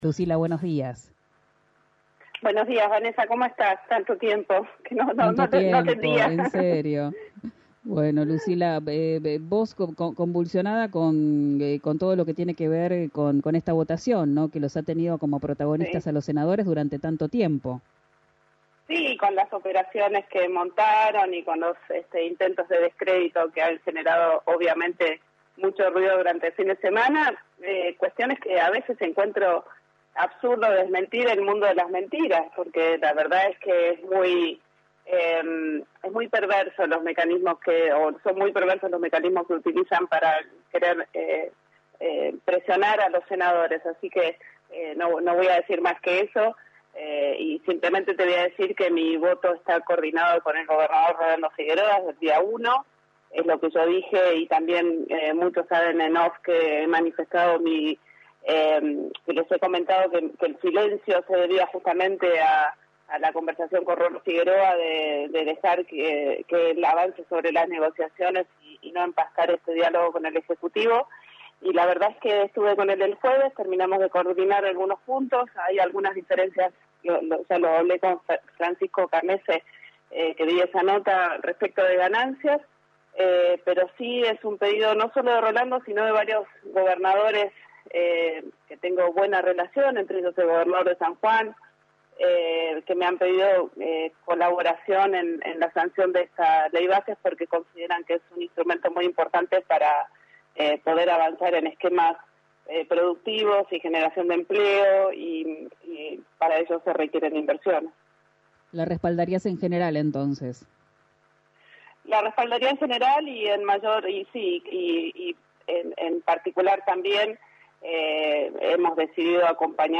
Escuchá a Lucila Crexell en RÍO NEGRO RADIO: